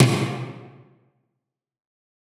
Tom_D1.wav